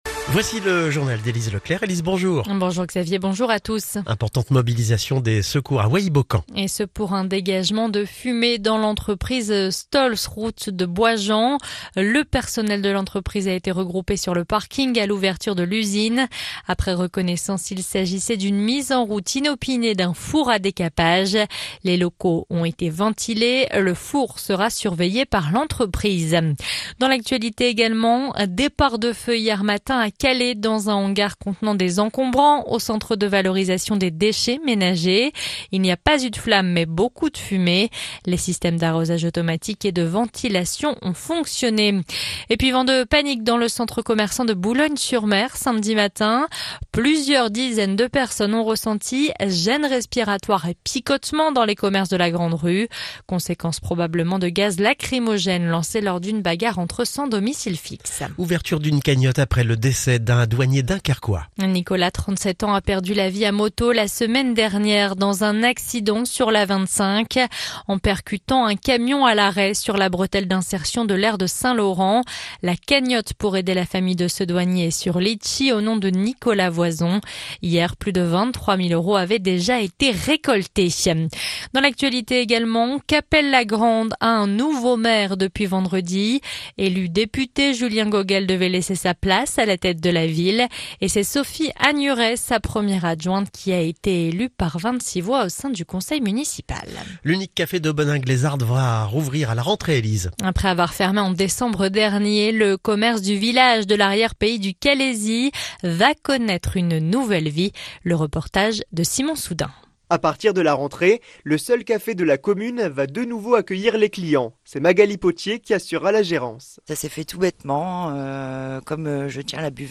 Le journal du lundi 5 août